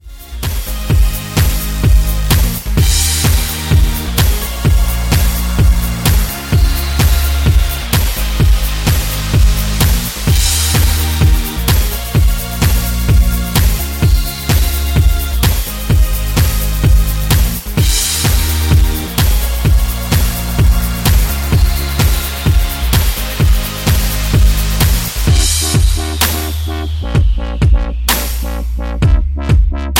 Backing track files: 2010s (1044)